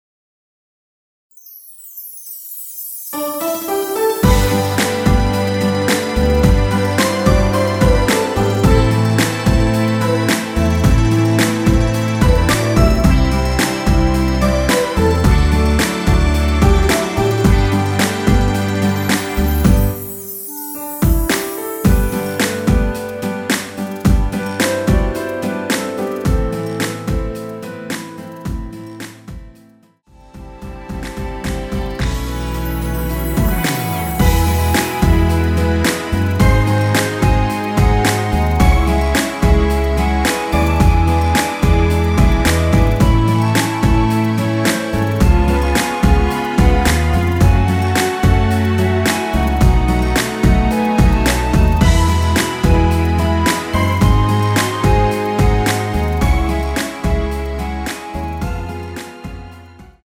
원키에서(-2)내린 멜로디 포함된 MR입니다.
전주가 너무길어 시작 Solo 부분22초 정도 없이 제작 하였으며
엔딩부분이 페이드 아웃이라 엔딩을 만들어 놓았습니다.(미리듣기 참조)
앞부분30초, 뒷부분30초씩 편집해서 올려 드리고 있습니다.
(멜로디 MR)은 가이드 멜로디가 포함된 MR 입니다.